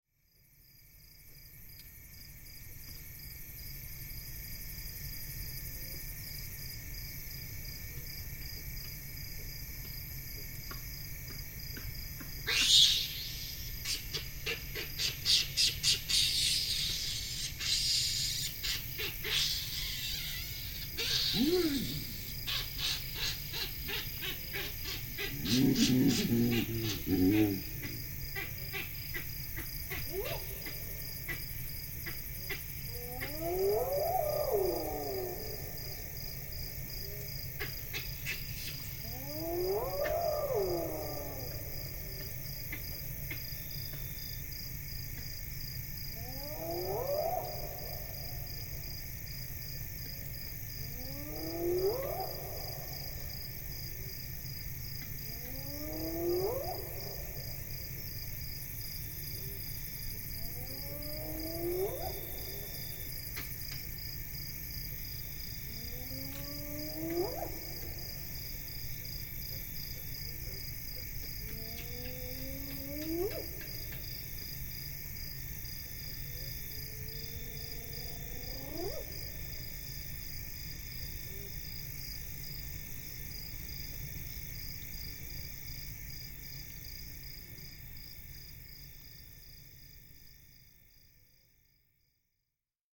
Baboons disturbed by hyena
A troop of baboons roosting in one of their favourite ana trees gets disturbed by a lone hyena calling nearby.
The continuous roar in the background is the thundering of the Victoria Falls, from about 10-15 km away.